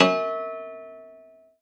53w-pno06-E3.wav